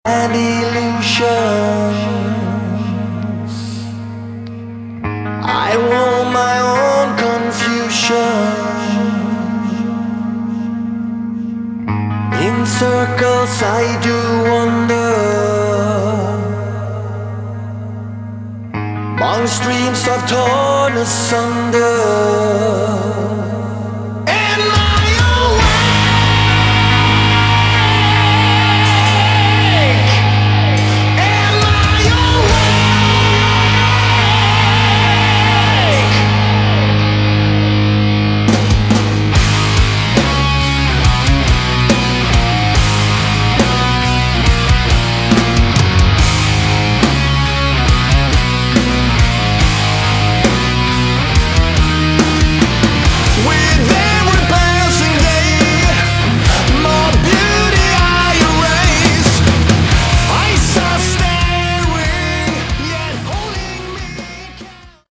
Category: Melodic Metal
Bass
Vocals
Guitars
Drums